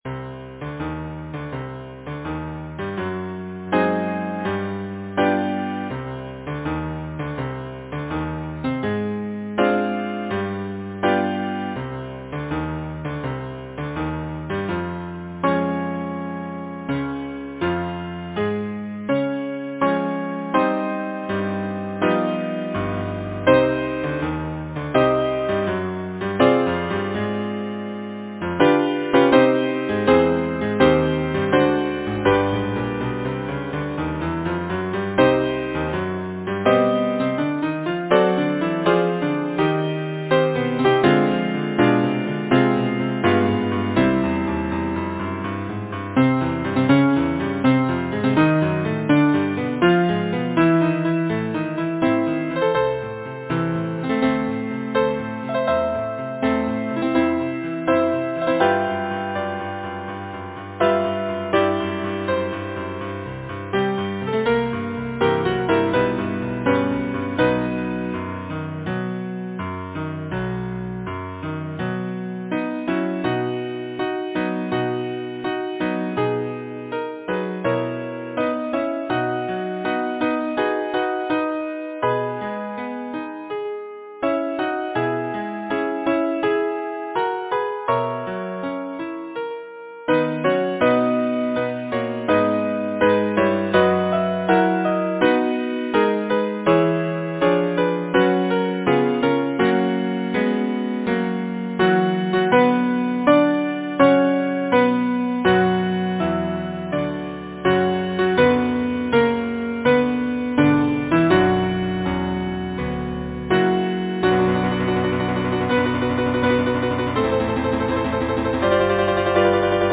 Title: Break, Break, Break Composer: Edward B. Birge Lyricist: Alfred Tennyson Number of voices: 4vv Voicing: SATB Genre: Secular, Partsong
Language: English Instruments: Keyboard